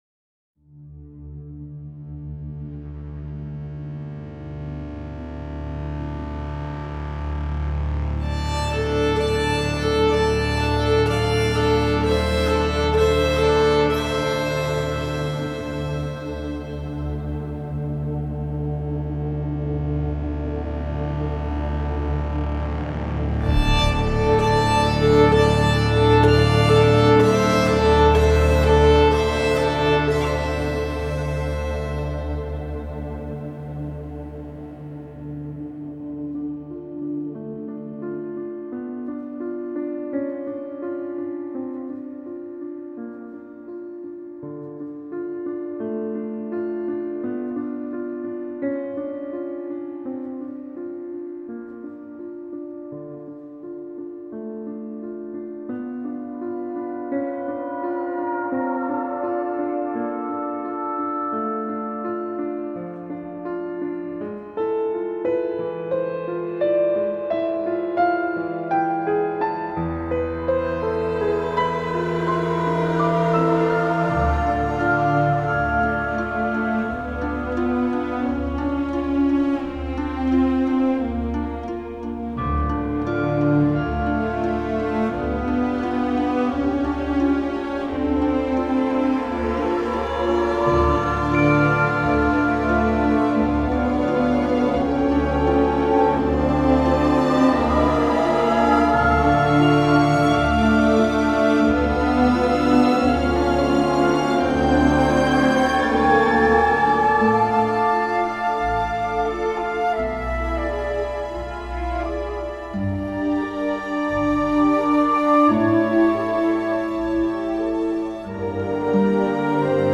Banda sonora completa